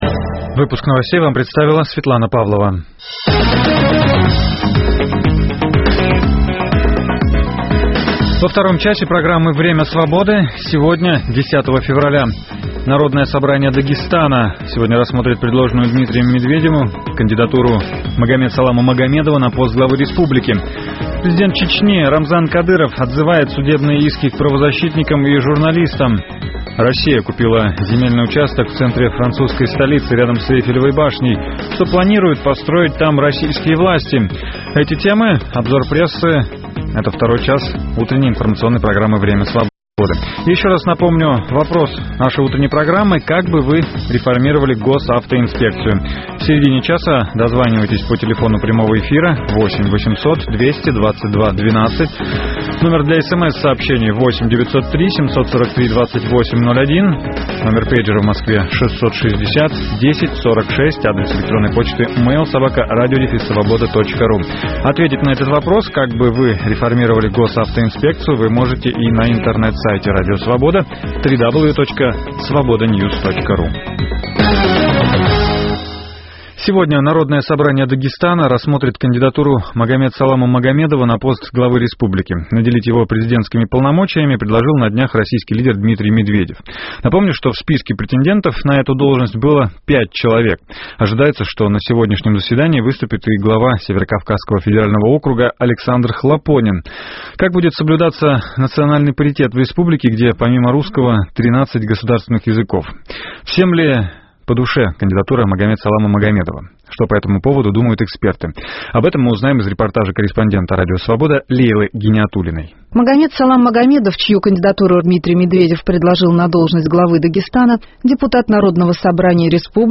С 9 до 10 часов утра мы расскажем о том, что готовит нам начинающийся день. Представим панораму политических, спортивных, научных новостей, в прямом эфире обсудим с гостями и экспертами самые свежие темы нового дня, поговорим о жизни двух российских столиц.